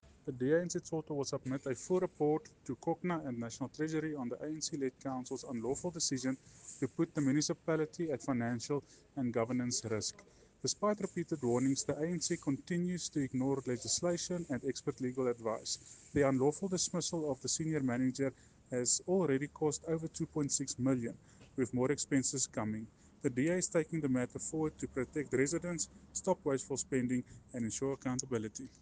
Afrikaans soundbites by Cllr Jose Coetzee and